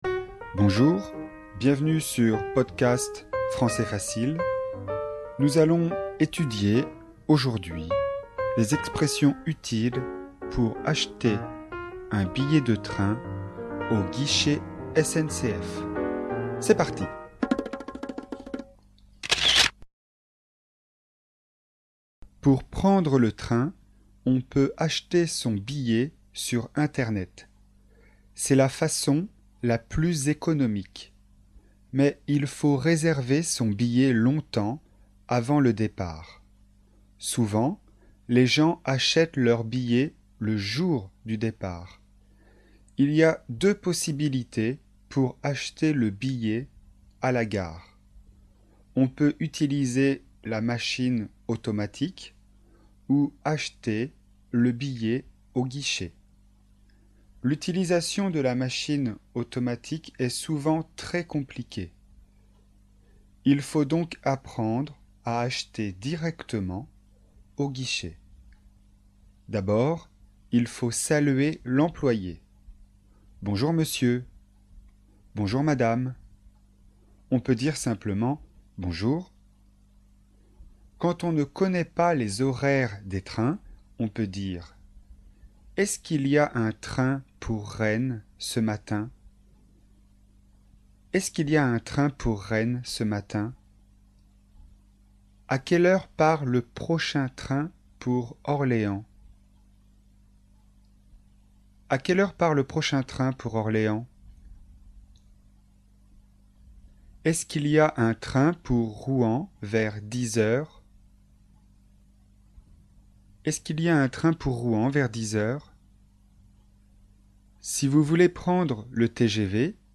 Leçon de communication, niveau débutant (A1), sur le thème du transport.